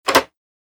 دانلود آهنگ موس 26 از افکت صوتی اشیاء
جلوه های صوتی
برچسب: دانلود آهنگ های افکت صوتی اشیاء دانلود آلبوم صدای کلیک موس از افکت صوتی اشیاء